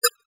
Holographic UI Sounds 24.wav